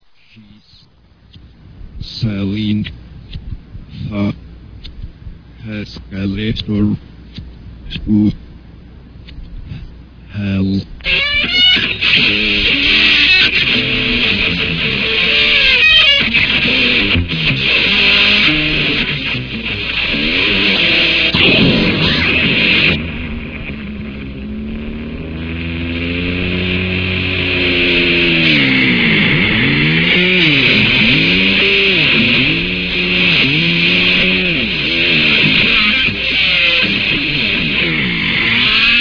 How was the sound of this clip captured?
Here are some sound samples from the June/July sessions: